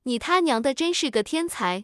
tts.wav